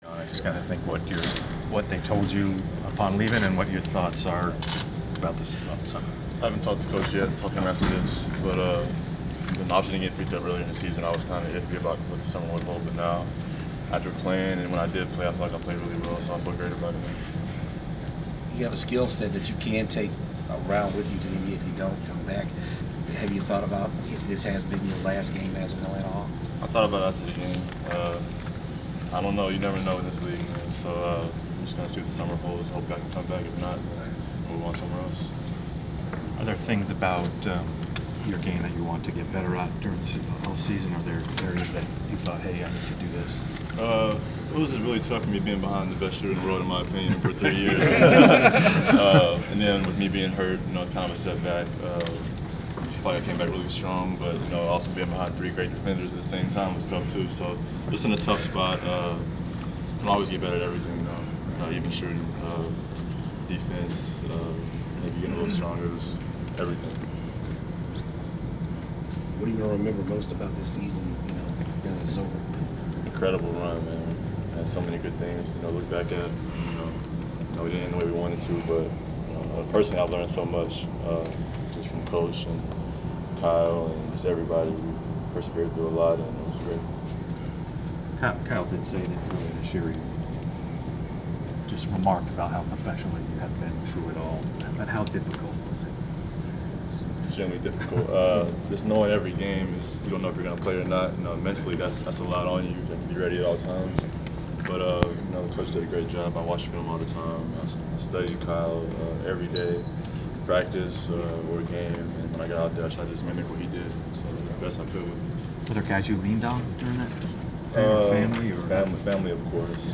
Inside the Inquirer: Postseason presser with Atlanta Hawks John Jenkins
The Sports Inquirer attended the media presser of Atlanta Hawks’ guard John Jenkins following the conclusion of his team’s season. Topics included his backup role with the Hawks and staying professional in game preparation, his future with the Hawks as a restricted free agent and offseason plans.